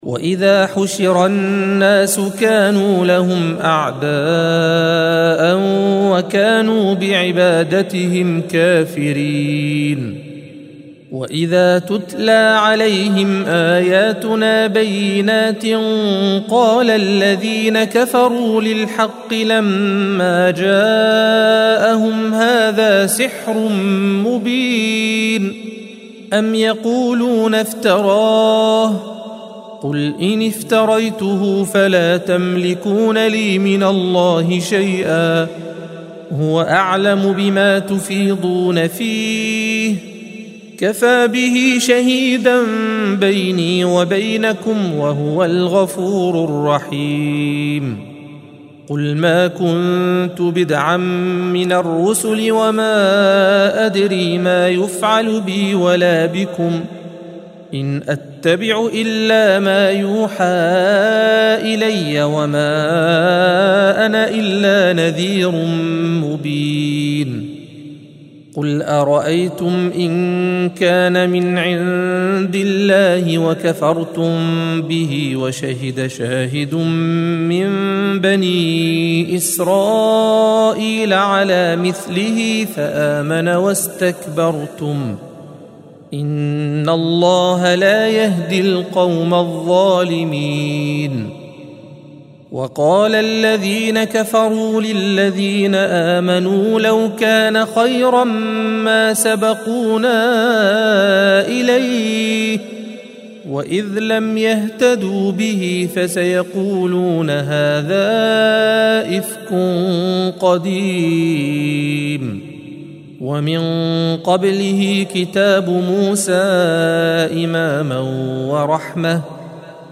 الصفحة 503 - القارئ